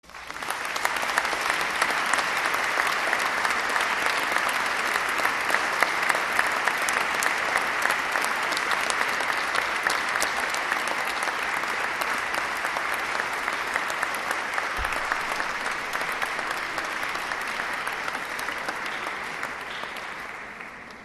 Hier mein Konzertmitschnitt vom 20. Dezember 2016, wie immer im puristischen Schnelldesign für alle, die mitgespielt, mitgesungen oder mitgeübt haben.
21_Applaus_fuer_BlechRIG.MP3